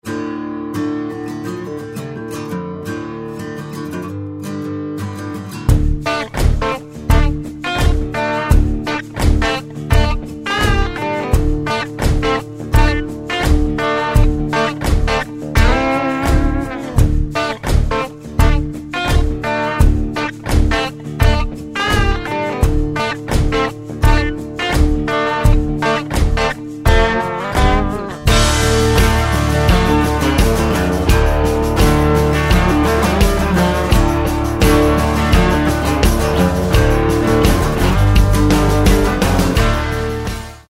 31.22M 试听： 一系列沼泽原声和电吉他曲目，dobro滑动舔和即兴演奏。